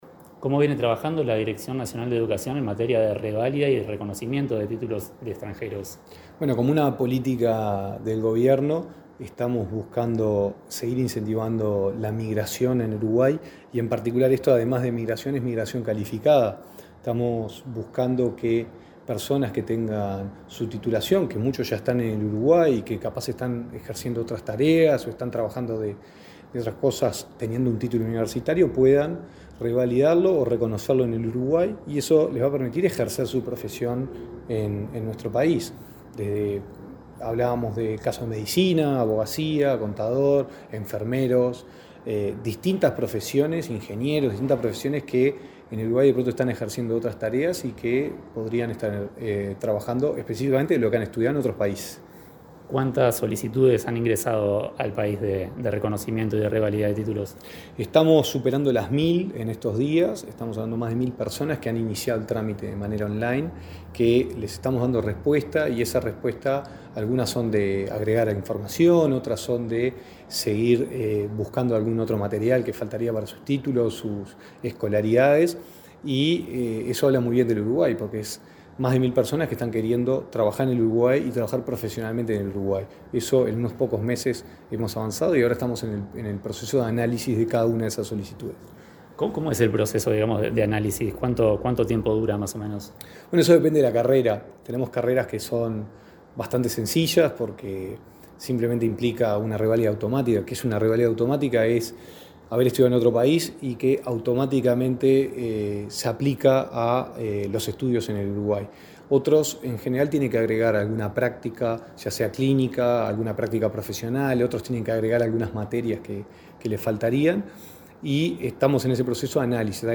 Entrevista al director de Educación, Gonzalo Baroni
El director de Educación del MEC, Gonzalo Baroni, dialogó con Comunicación Presidencial sobre las reválidas y reconocimiento de títulos a extranjeros.